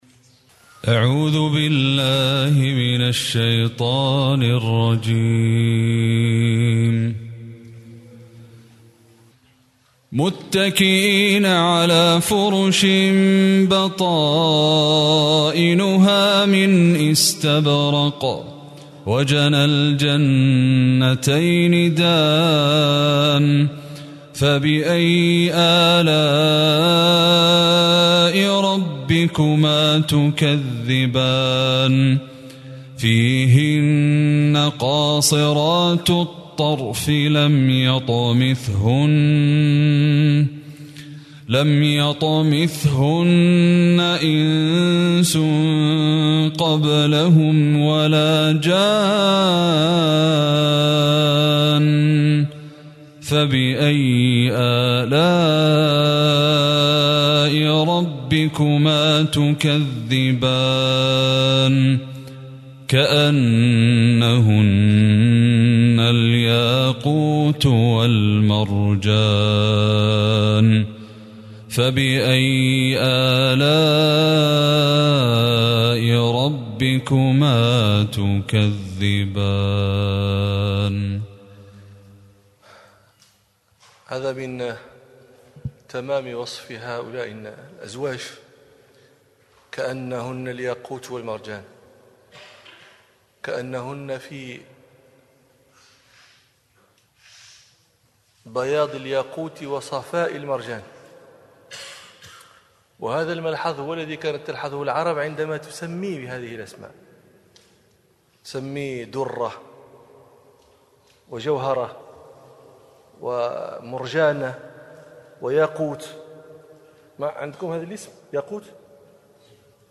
مجلسان في كل يوم خلال ثلاثة أيام في شهر شعبان 1446هـ في مملكة البحرين.